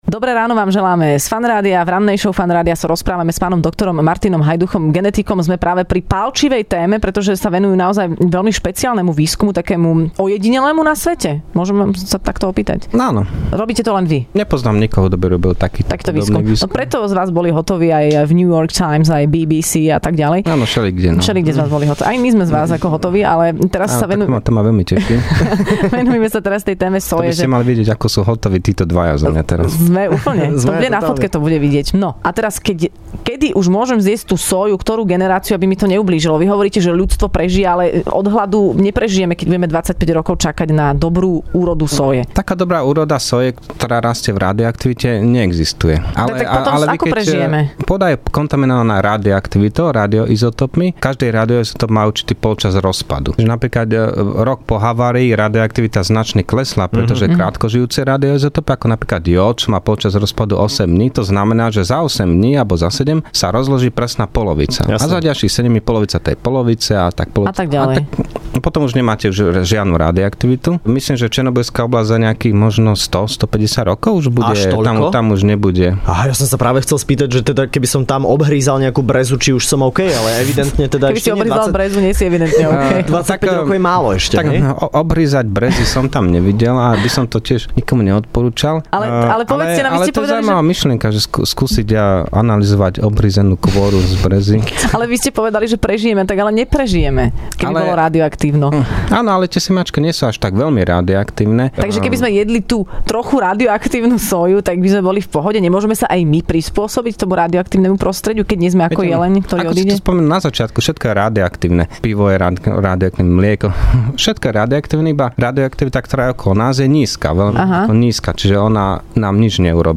Hosťom v Rannej šou bol genetik